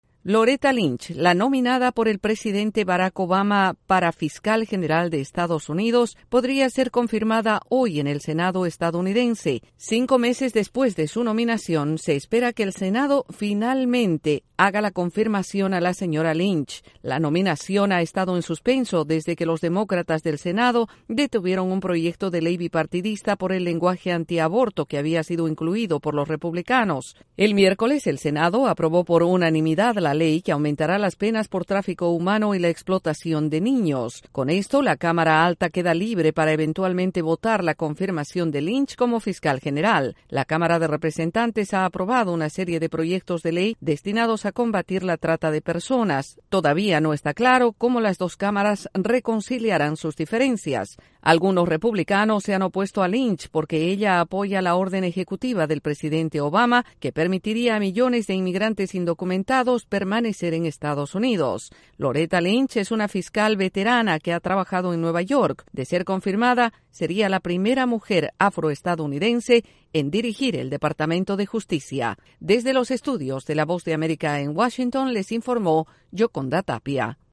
El Senado estadounidense se apresta a votar para confirmar a la nueva Secretaria de Justicia. Desde la Voz de América en Washington DC informa